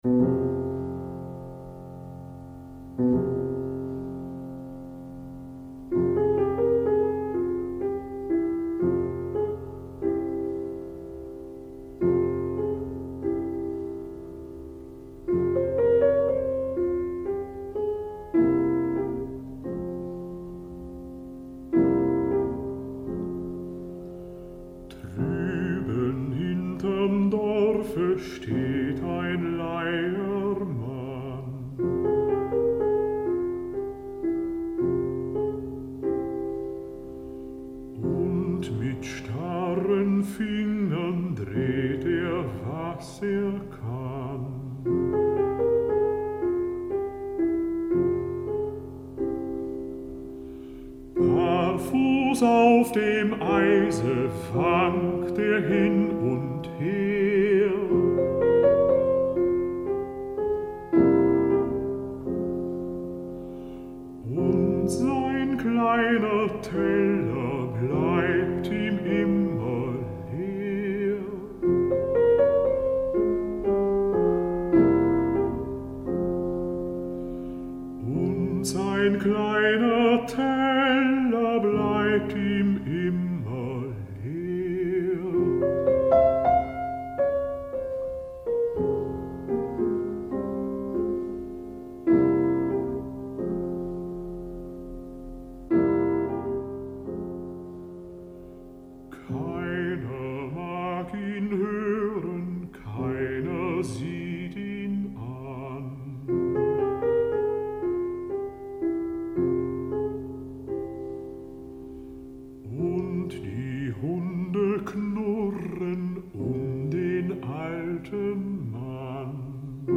سیکلِ آوازی